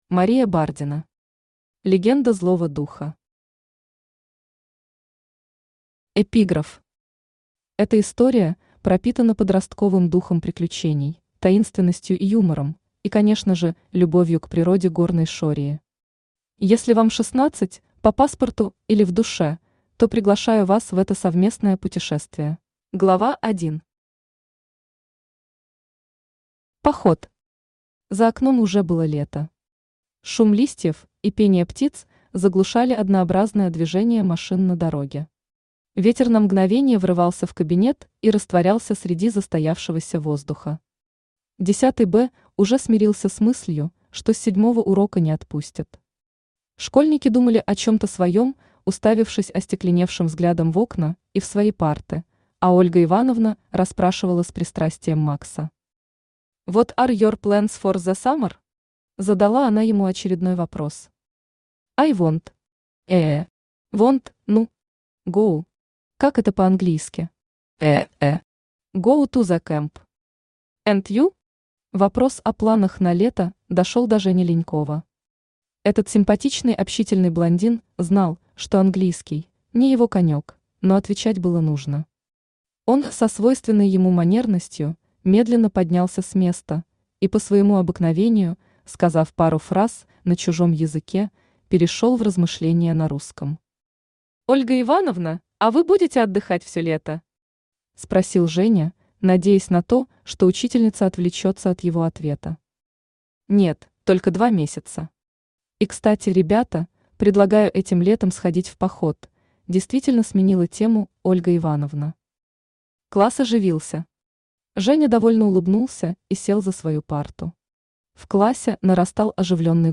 Аудиокнига Легенда злого духа | Библиотека аудиокниг
Aудиокнига Легенда злого духа Автор Мария Бардина Читает аудиокнигу Авточтец ЛитРес.